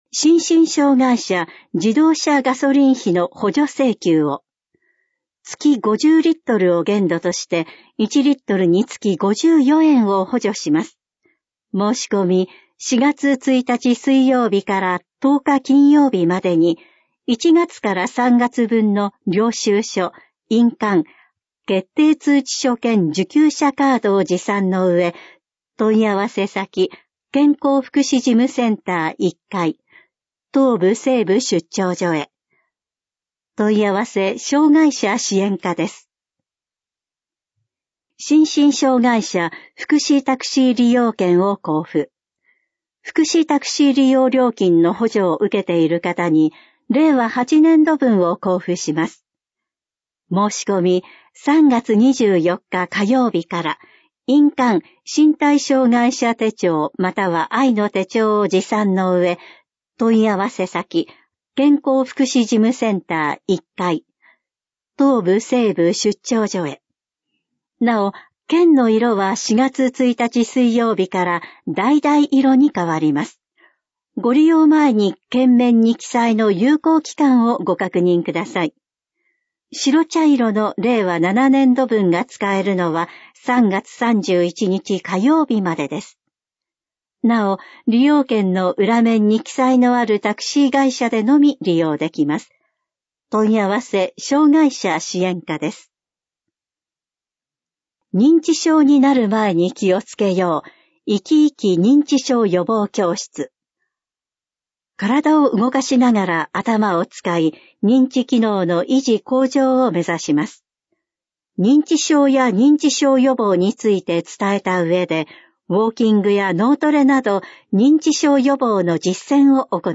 市報音声版「声のたより」市報こだいら2026年3月20日号音声版｜東京都小平市公式ホームページ